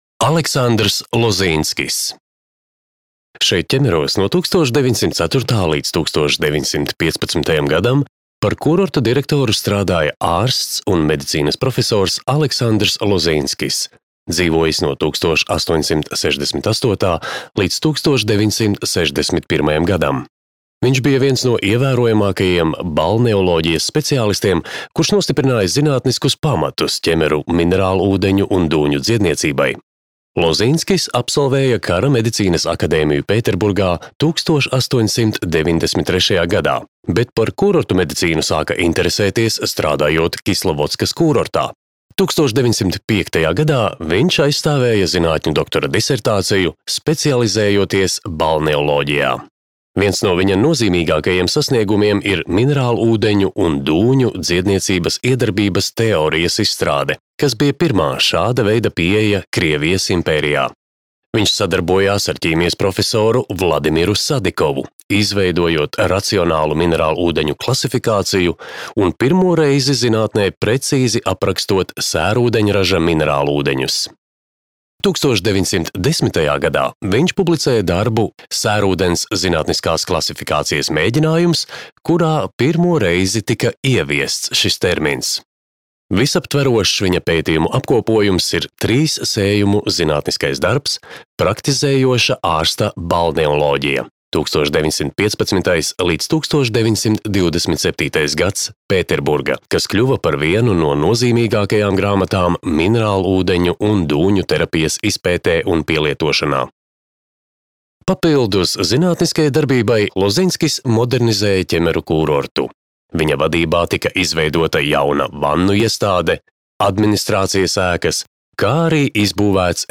Ķemeru kūrorta parka audiogids